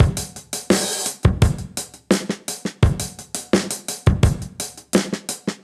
Index of /musicradar/sampled-funk-soul-samples/85bpm/Beats
SSF_DrumsProc1_85-04.wav